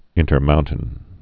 (ĭntər-mountən)